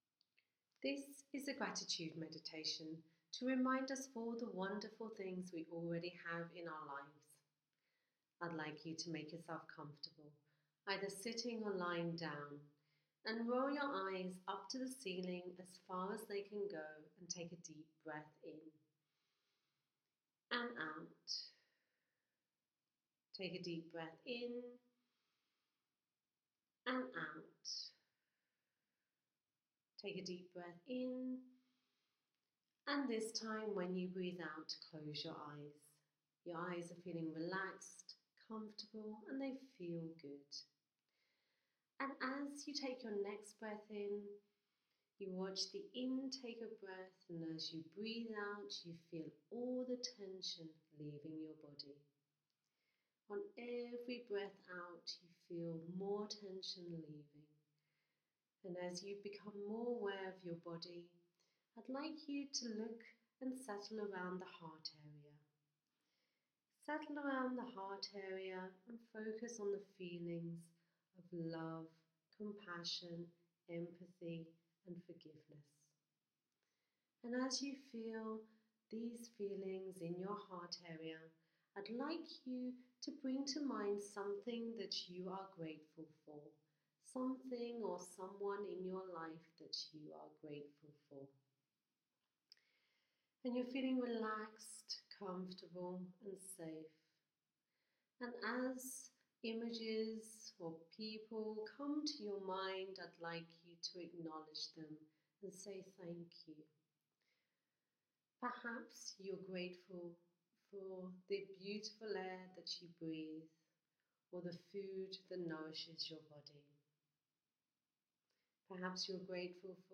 Or join me on a 5 minute guided gratitude meditation.